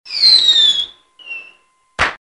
Свист сигнальной ракеты в ночи